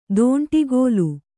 ♪ dōṇṭigōlu